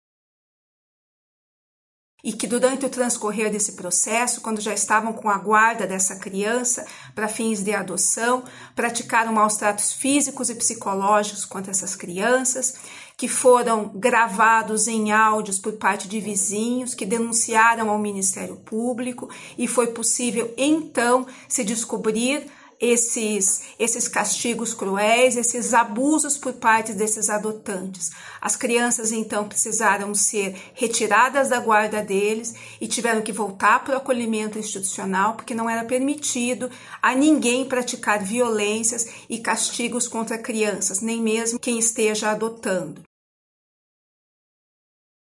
Após algumas semanas, o Conselho Tutelar foi acionado por vizinhos, que fizeram áudios com xingamentos e gritos do casal e choro das crianças, como informou a promotora de Justiça Fernanda Nsgl Garcez.